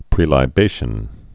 (prēlī-bāshən)